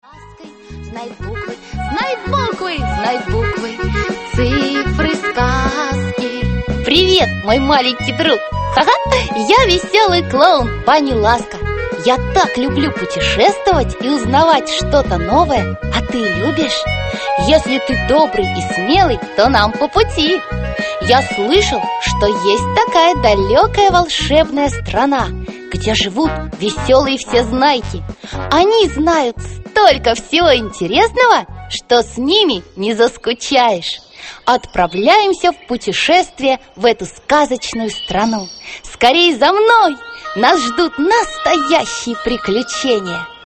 Аудиокнига Веселые уроки Баниласки. Буквы, цифры, формы, цвета | Библиотека аудиокниг